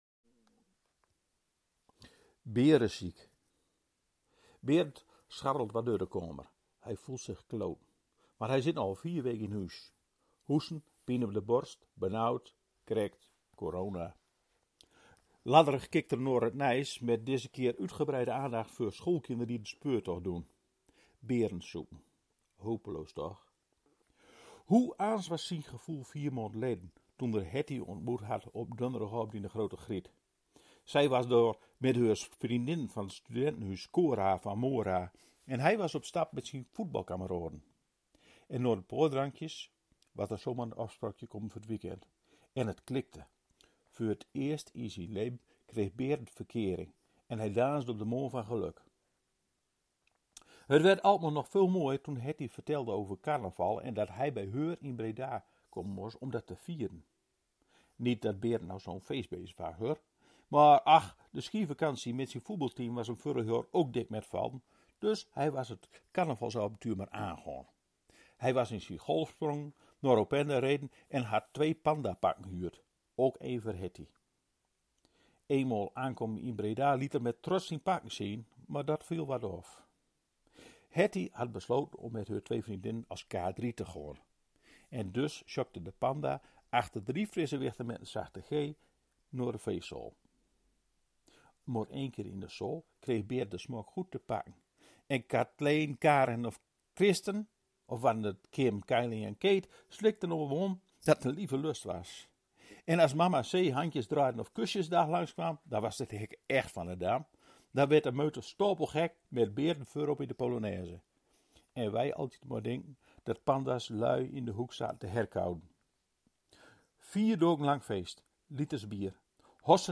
De vertellers van vertelcollectief  ‘Kom op Verhoal’ vertellen elke zaterdagochtend om 9:45 een verhaal op Radio Noord.